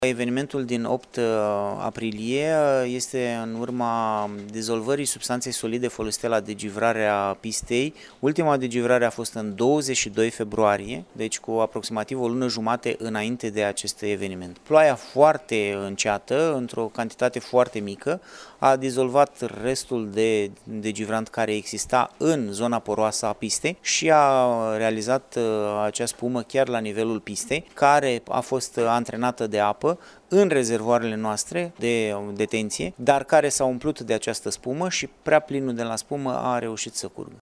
UPDATE 10.04.2019, ora 14:07  – Conducerea Aeroportului Iaşi a recunoscut, astăzi, într-o conferinţă de presă, că poluarea de pe lacul Ciric III Veneţia a fost cauzată de agentul dejivrant de la pistă folosit în urmă cu aproape 6 săptămâni.